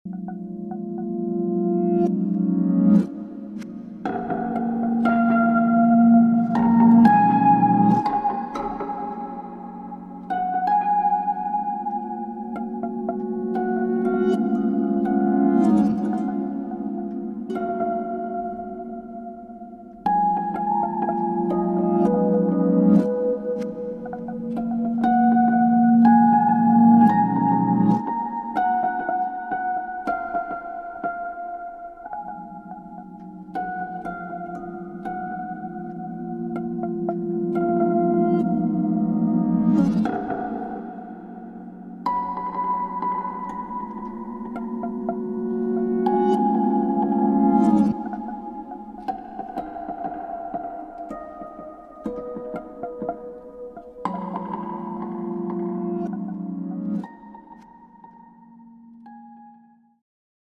Niche